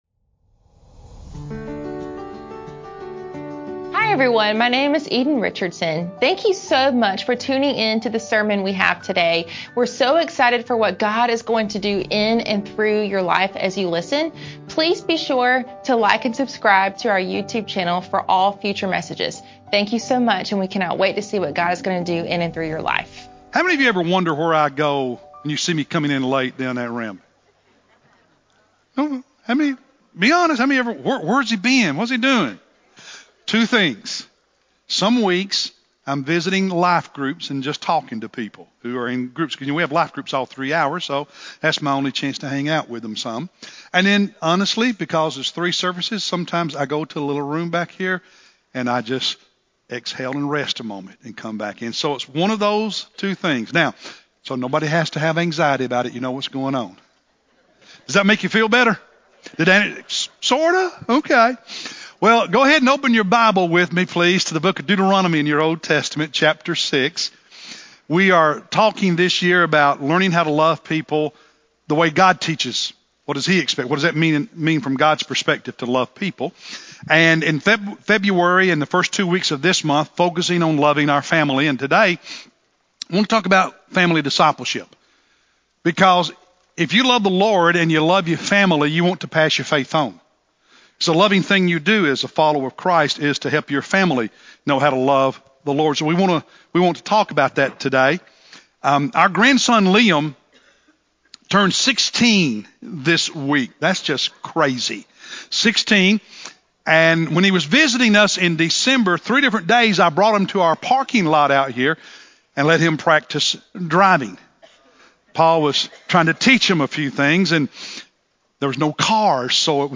March-1-Sermon-CD.mp3